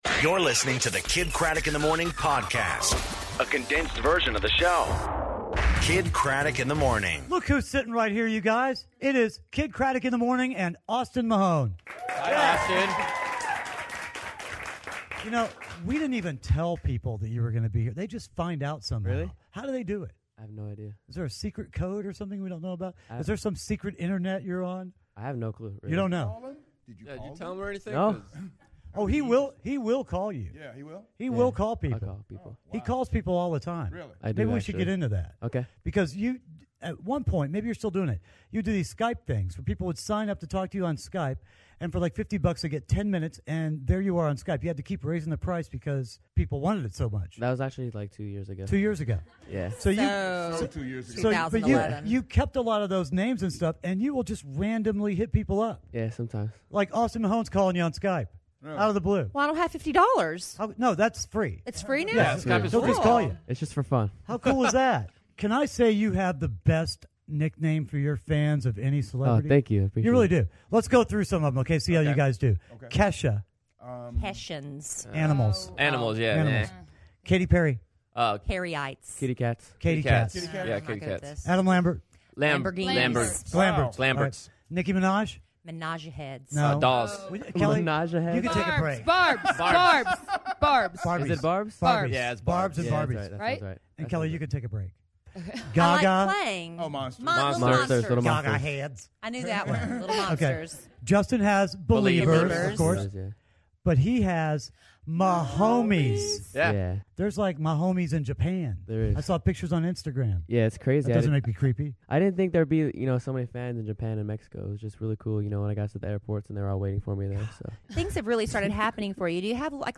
Austin Mahone In Studio, Kidd's Drive To Work, And Cody Simpson In The CanalSide Lounge!